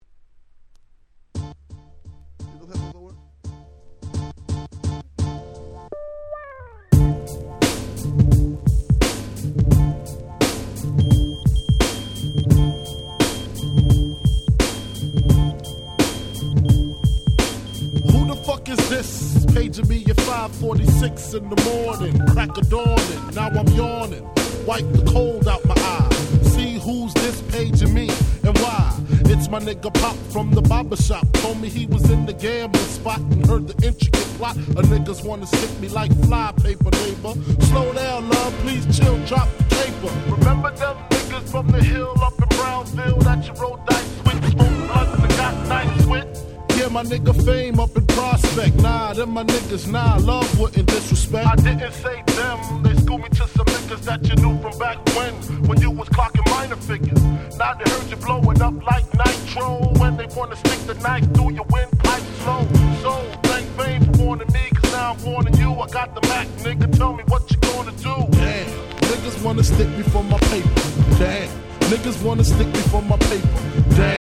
95' Super Hit Hip Hop !!
90's Boom Bap